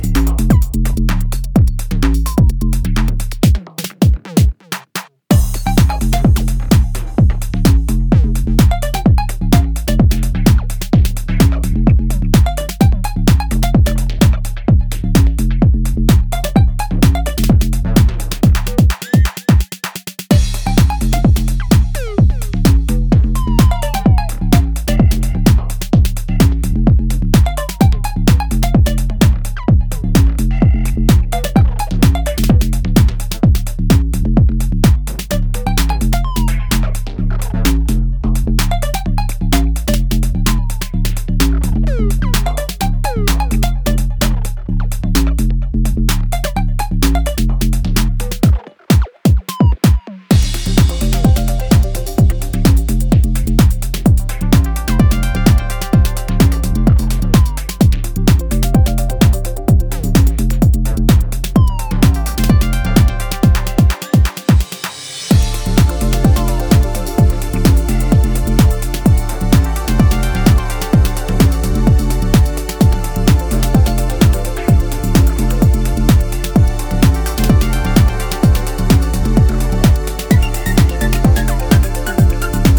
who serves up a banging house EP